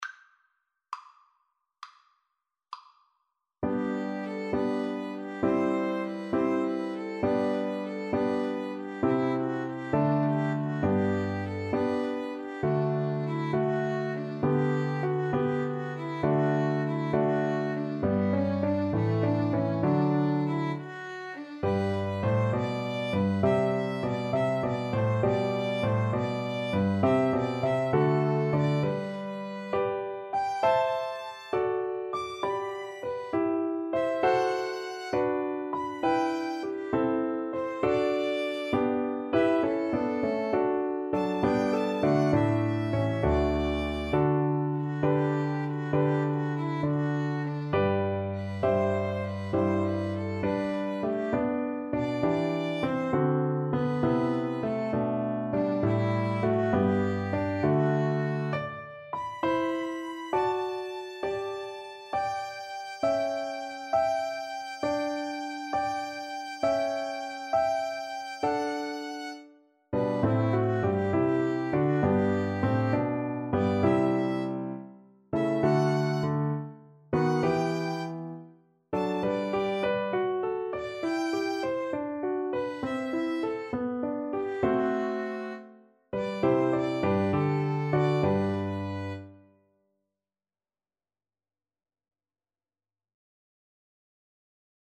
Largo
Classical (View more Classical Oboe-Violin Duet Music)